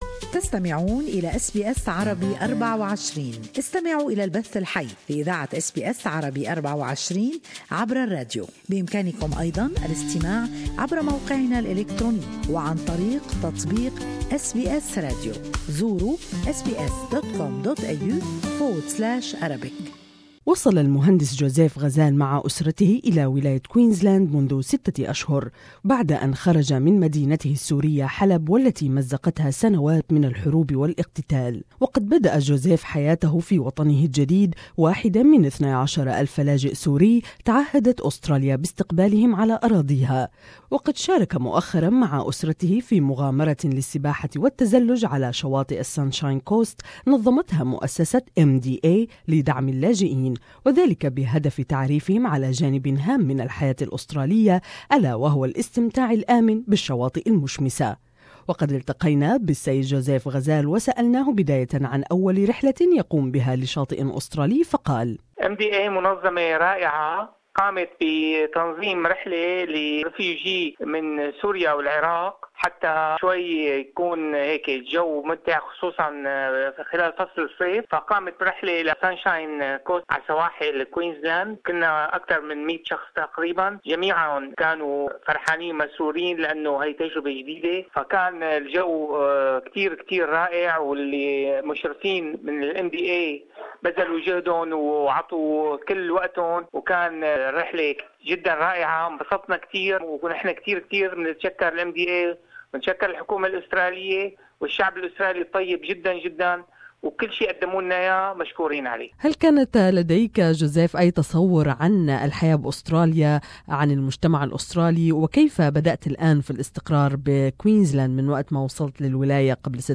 More in this interview